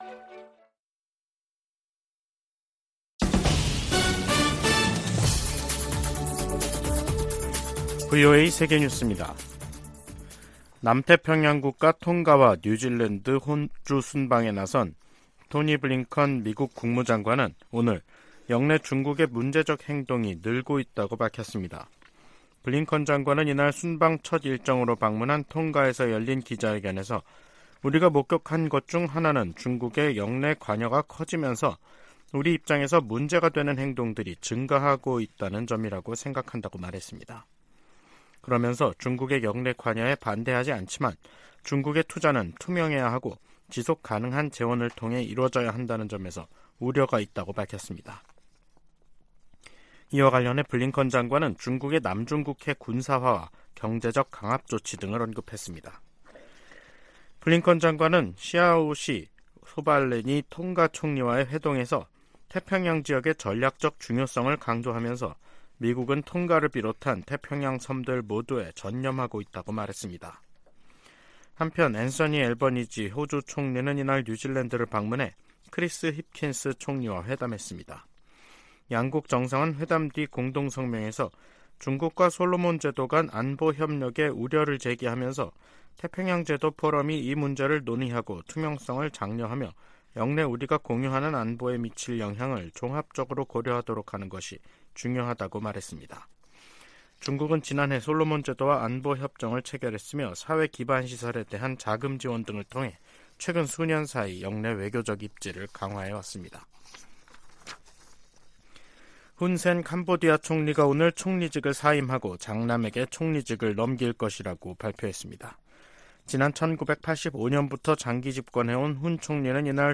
VOA 한국어 간판 뉴스 프로그램 '뉴스 투데이', 2023년 7월 26일 2부 방송입니다. 북한의 '전승절' 행사에 중국과 러시아 대표단이 참가하면서 북중러 3각 밀착이 선명해지는 것으로 분석되고 있습니다. 미 국무부는 중국과 러시아가 북한의 불법 활동을 자제하는 역할을 해야 한다고 강조했습니다. 미 국방부는 월북한 미군 병사와 관련해 아직 북한 측의 응답이 없다고 밝혔습니다.